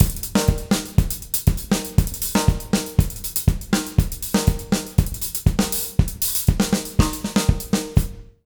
120ZOUK 05-R.wav